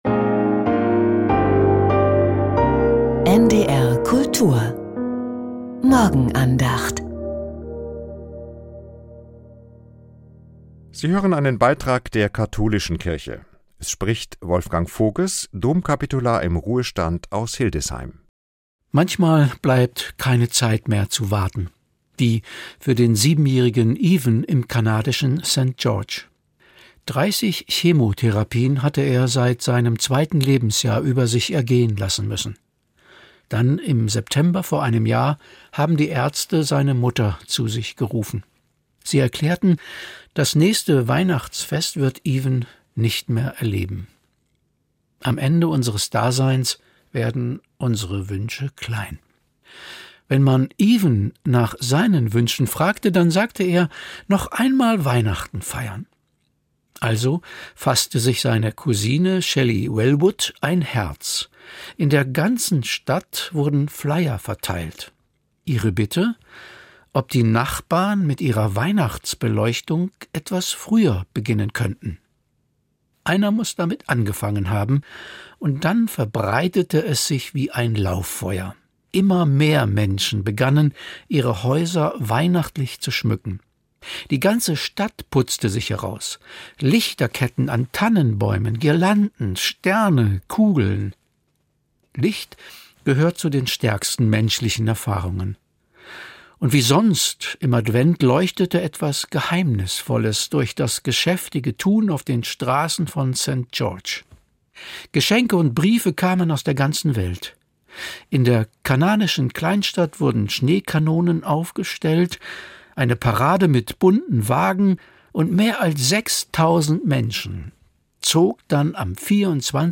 Die Morgenandacht von Domkapitular